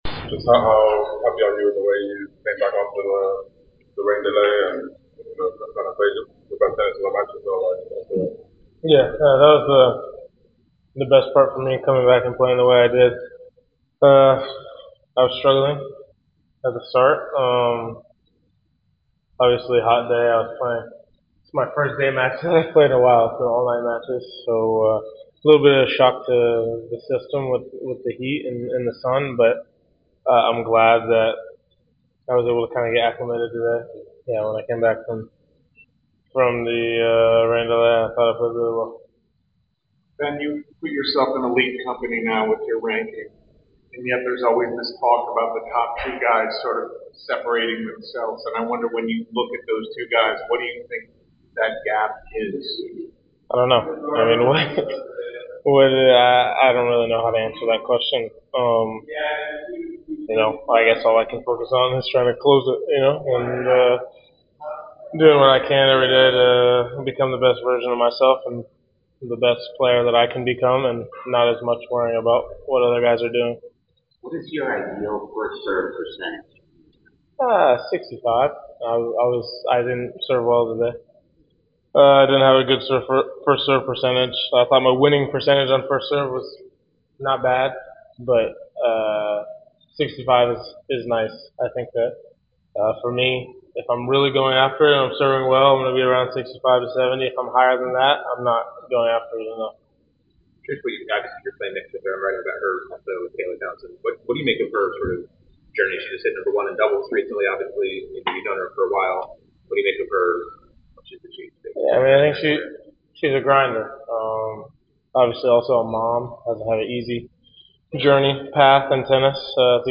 Ben Shelton post-match interview after defeating Roberto Bautista Agut 7-6. 6-3 in the 3rd Round of the Cincinnati Open.